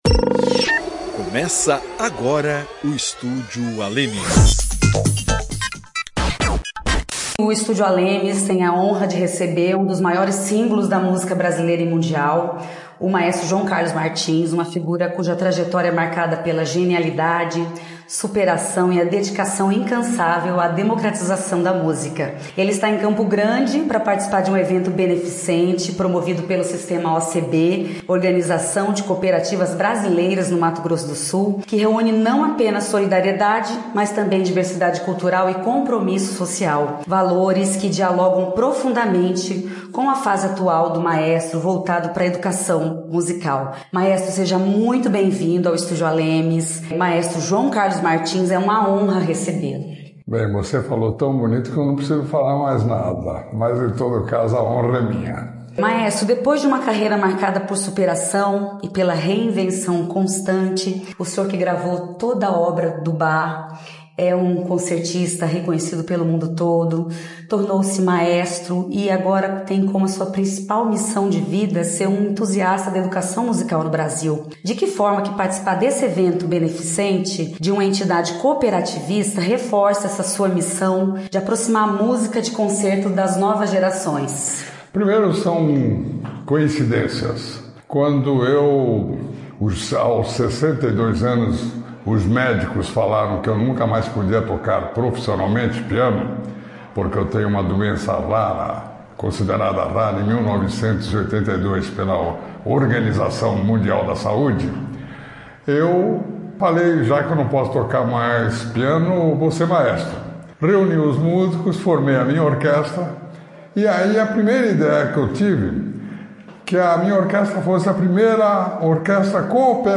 Maestro João Carlos Martins é o entrevistado do Estúdio ALEMS Com a dedicação de um atleta e a alma de um poeta, o maestro inicia uma nova fase, a de educador musical.